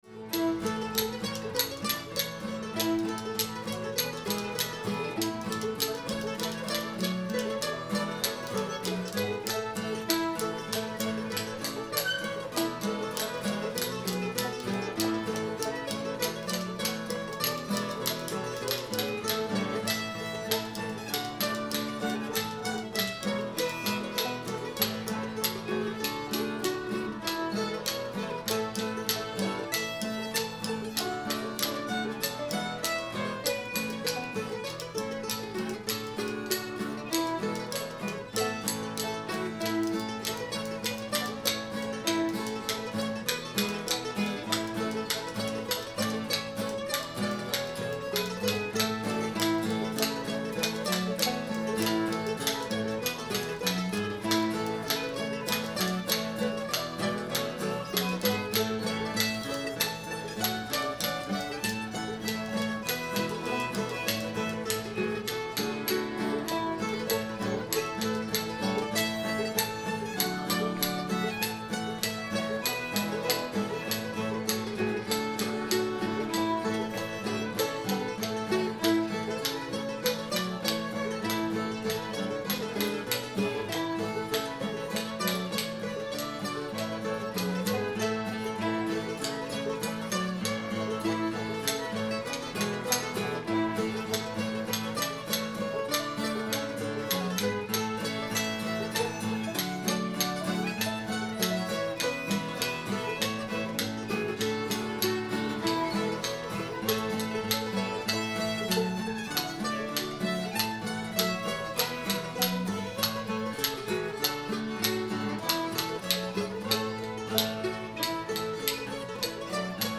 sweet nell [A]